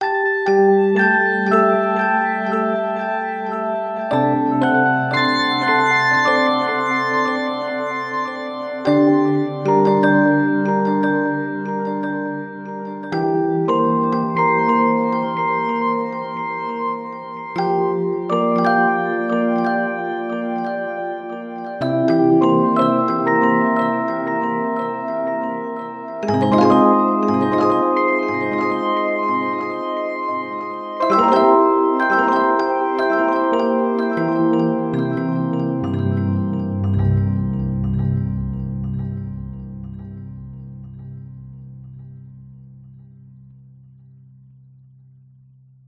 Honest i'll get back to natural sounds soon but for now here's a very quick live musical strummy (that can't be a word?) pseudo lullabyebyes to set the scene for getting into calmer places (or more likely my missing theme to an inadvertently creepy children's school educational programme in the 70s).